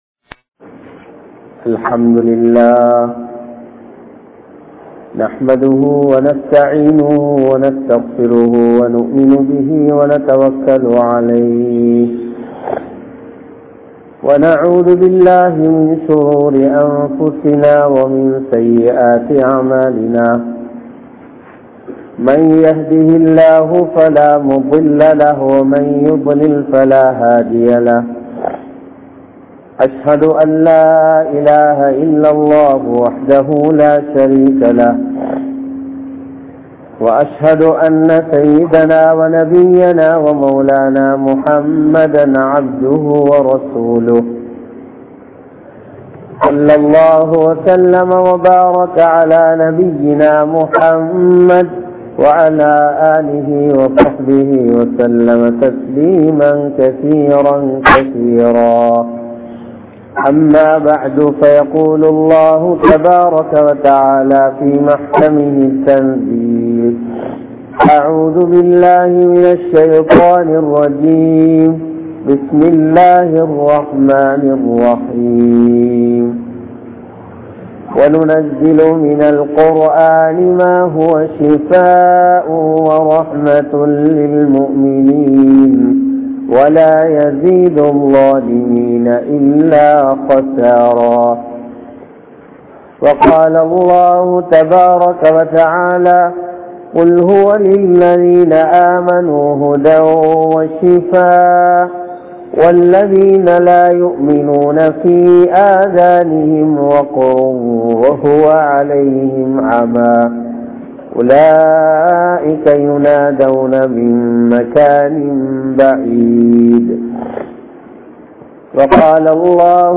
Kavalaiyai Neekkum Vidayangal (கவலையை நீக்கும் விடயங்கள்) | Audio Bayans | All Ceylon Muslim Youth Community | Addalaichenai